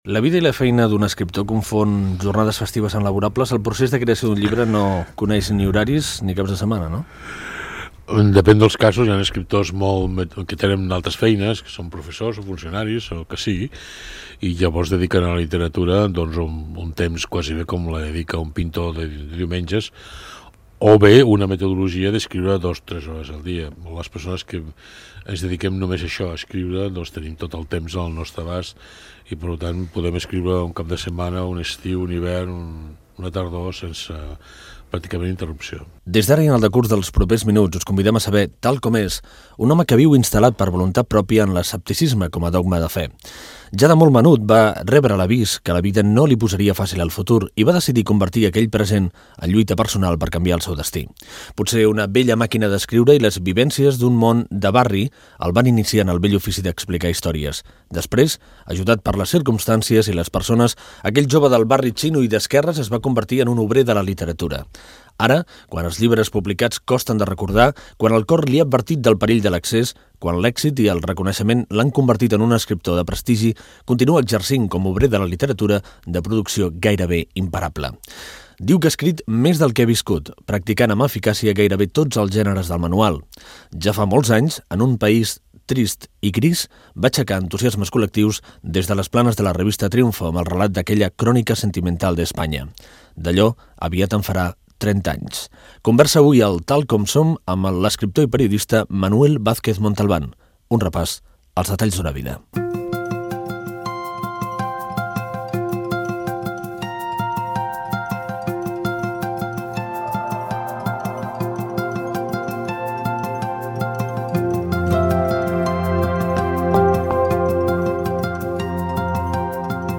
Fragment d'una entrevista a l'escriptor i periodista Manuel Vázquez Montalbán.